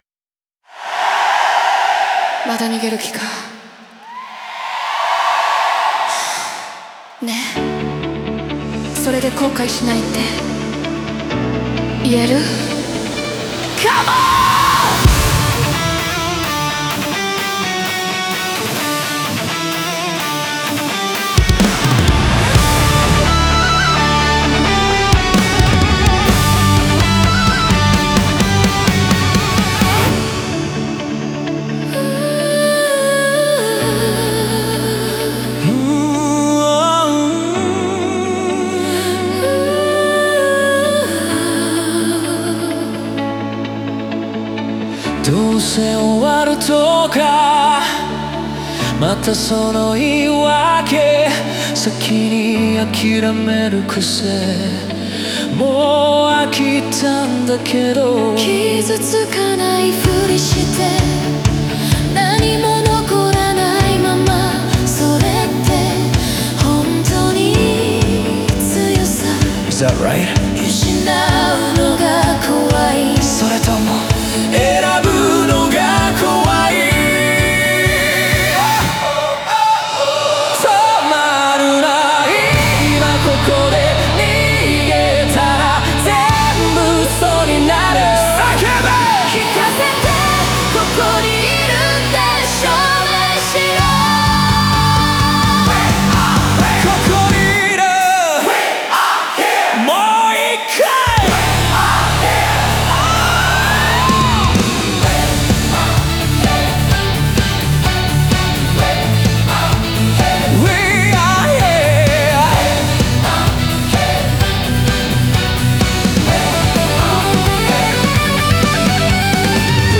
ここにいるって叫べ(Live Rock)
オリジナル曲♪
デュエットは内面の葛藤を可視化し、対話と衝突を通じて決断へと向かわせる。前半は問いかけと挑発が中心だが、観客コールが加わることで個人の迷いが集団の意志へと変化していく。中盤のギターソロは言葉を超えた感情の独白として機能し、迷い、覚悟、解放の三段階を音で表現する。最終的に「ここにいる」という言葉は宣言となり、歌い手・観客・バンド・オーケストラが一体となって現在を選び取る瞬間を生み出す。